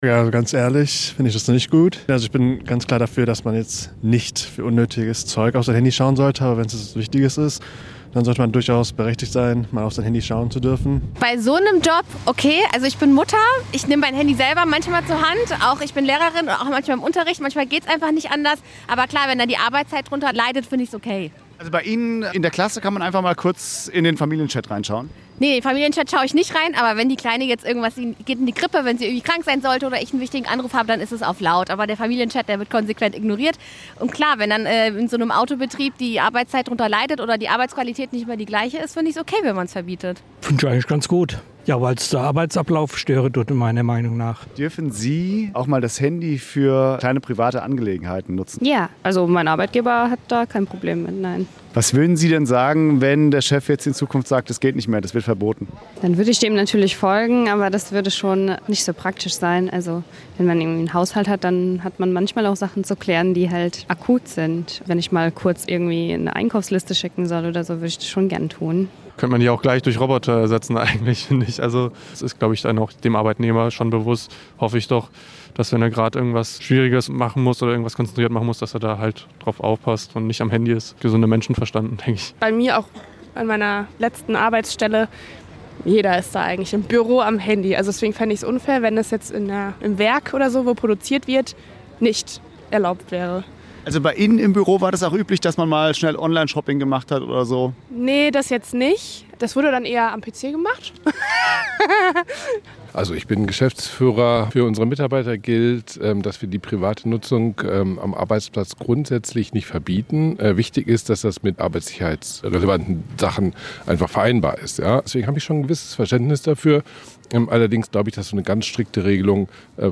Bei einer Straßenumfrage gehen die Meinungen über den Schritt weit auseinander.
Eine Frau hält die Begründung für stichhaltig.